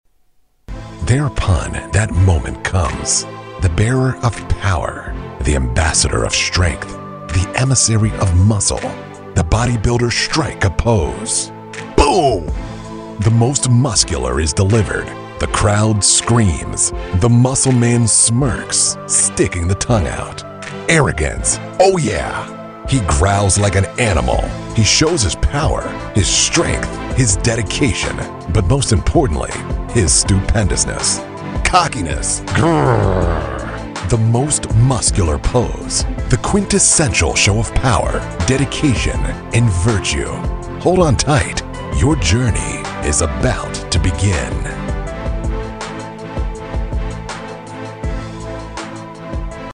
男英102
男英102 美式英语 企业宣传 讲解成熟稳重 低沉|激情激昂|大气浑厚磁性|沉稳|调性走心|素人